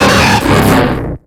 Cri d'Élecsprint dans Pokémon X et Y.